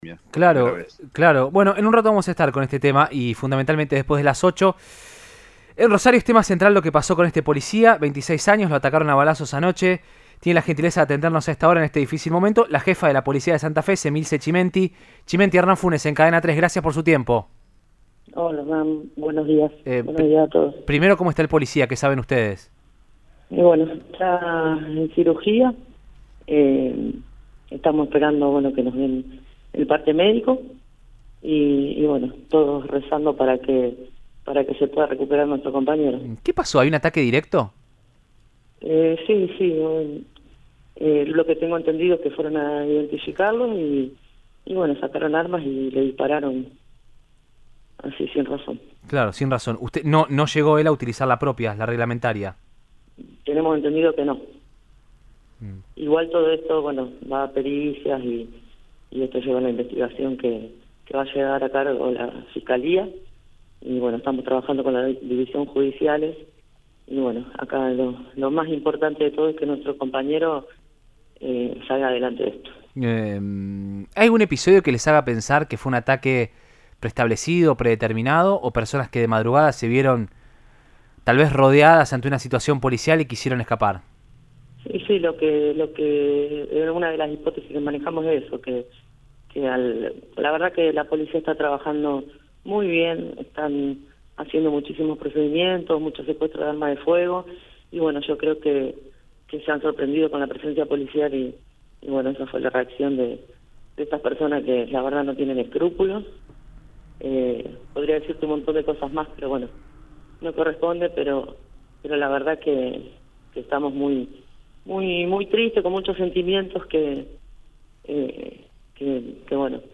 Emilce Chimenti, jefa de la Policía de la provincia de Santa fe, habló en Radioinforme 3, por Cadena 3 Rosario, y expresó: “Fueron a identificarlos, sacaron armas y le dispararon sin razón. Estas personas que no tienen escrúpulos”.